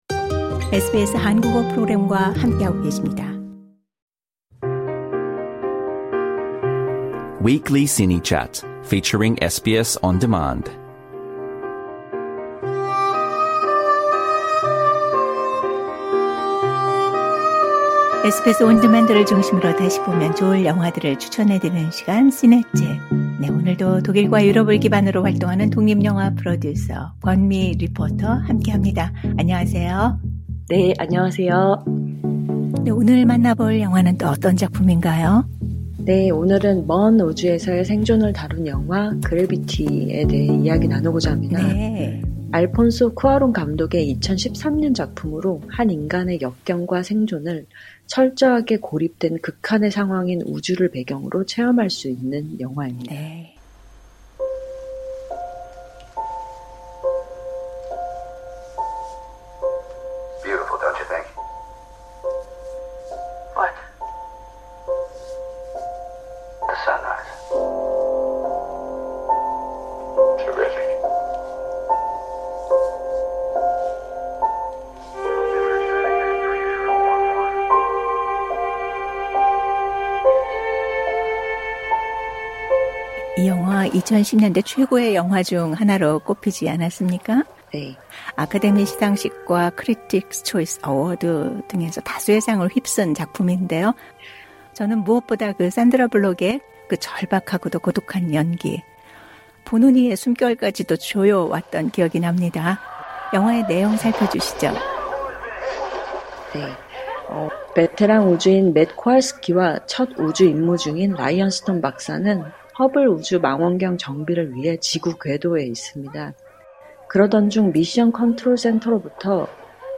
Trailer Audio Clip